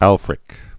(ălfrĭk) Known as "Grammaticus." 955?-1020?